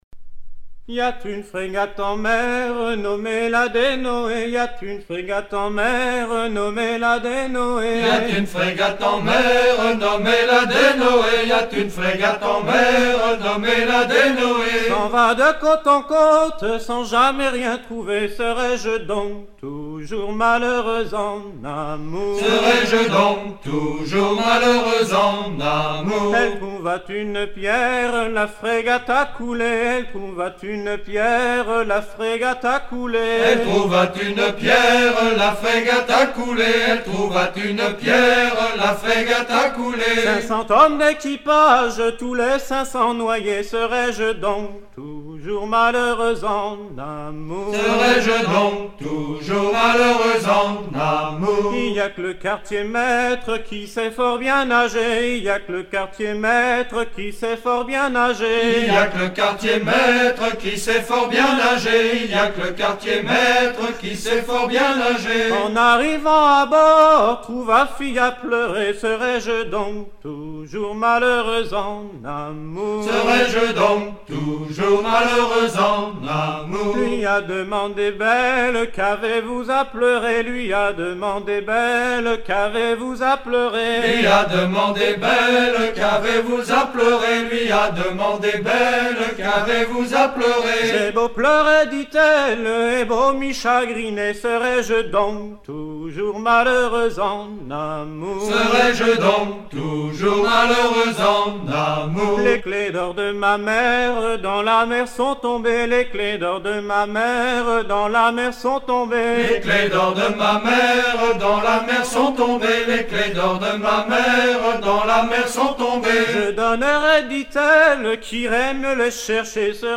Recueilli en 1980
danse : bal paludier
Genre laisse